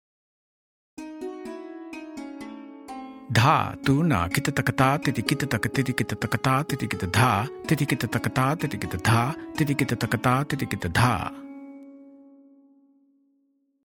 Demonstrations
Spoken with Lahra
BK2-Video-126-Lehra.mp3